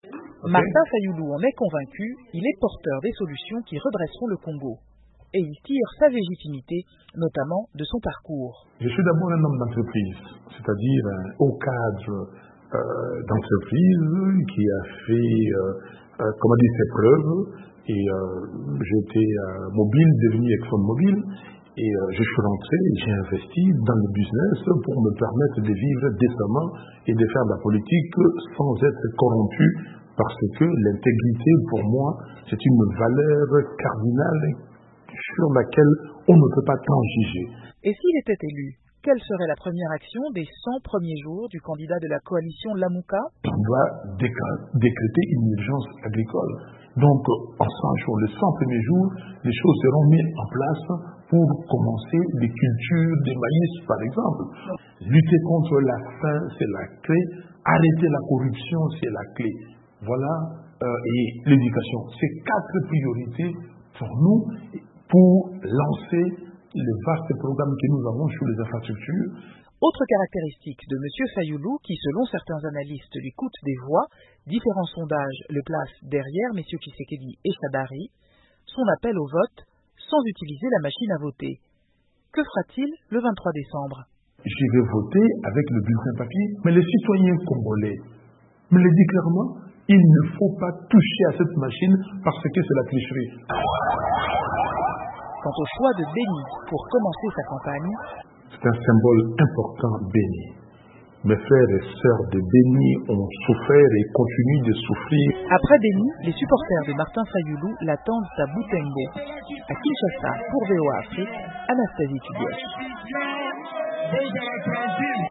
Le candidat de la coalition Lamuka a choisi la ville pour commencer sa campagne. Entretien